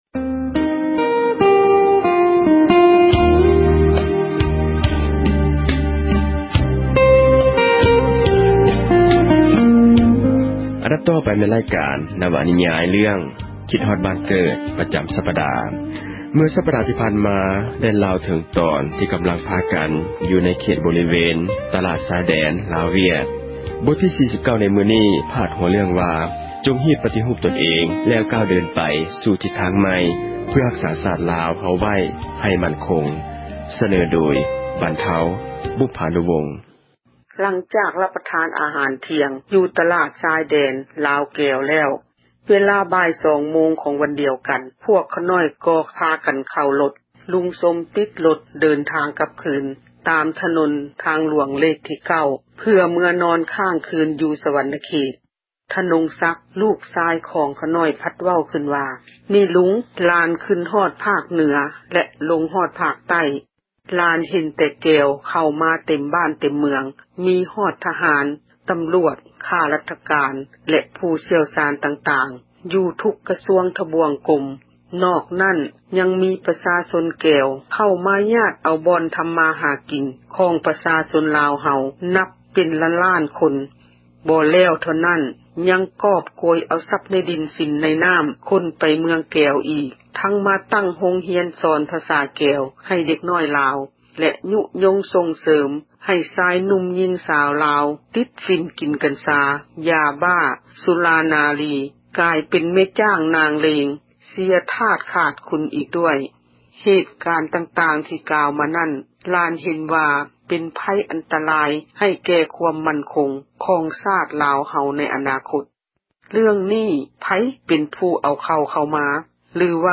ນິຍາຍ ເຣື້ອງ ຄິດຮອດບ້ານເກີດ ປະຈຳສັປດາ ບົດທີ 48.